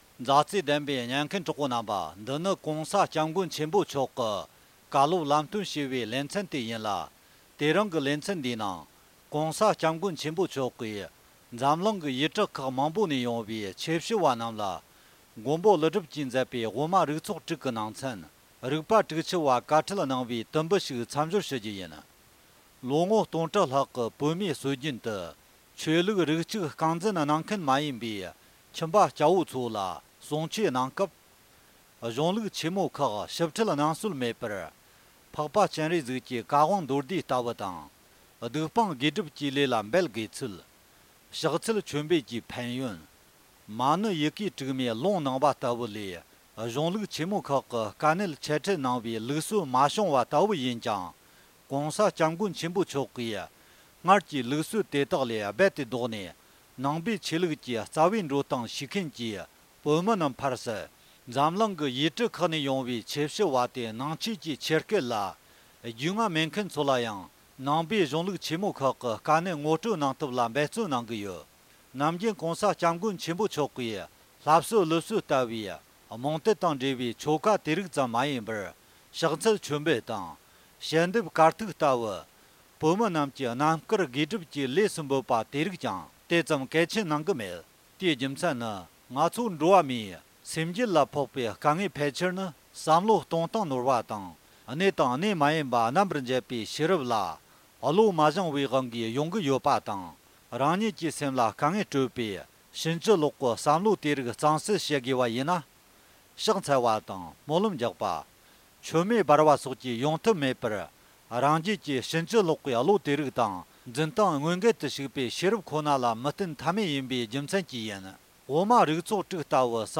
མགོན་པོ་ཀླུ་སྒྲུབ་ཀྱིས་མཛད་པའི་དབུ་མ་རིག་ཚོགས་དྲུག་གི་ནང་ཚན། རིག་པ་དྲུག་བཅུ་པའི་བཀའ་ཁྲི་གནང་བའི་དུམ་བུ་ཞིག་སྙན་སྒྲོན་ཞུ་རྒྱུ་ཡིན།།